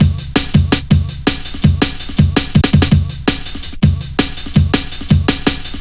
Index of /90_sSampleCDs/Zero-G - Total Drum Bass/Drumloops - 3/track 44 (165bpm)